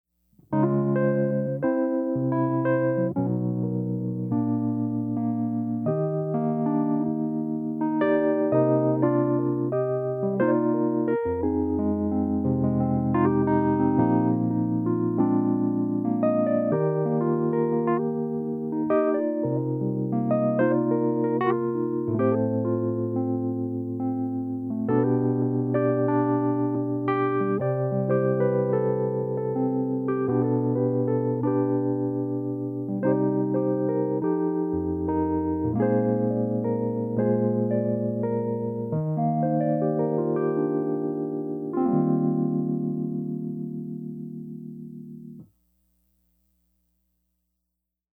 Wurlitzer 106P Improv:
106P-improv.mp3